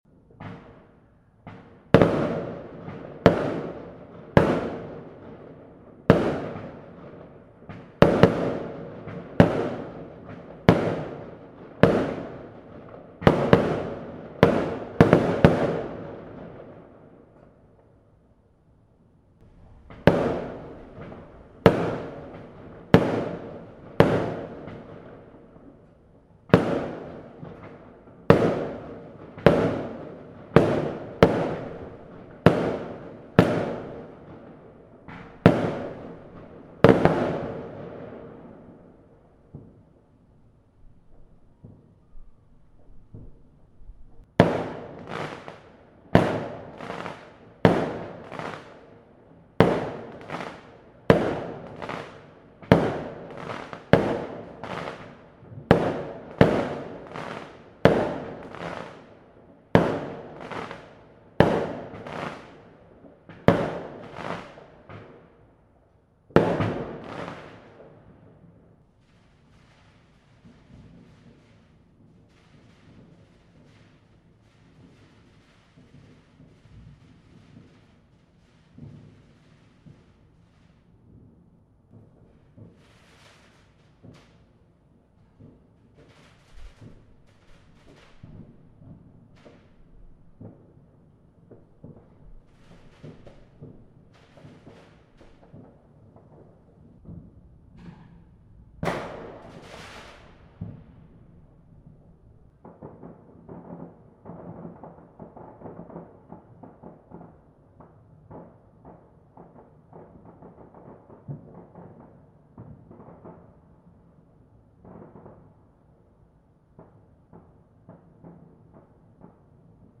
Extremely Loud Fireworks 6 November 2021
There were many fireworks in West London over the weekend but these were some of the loudest.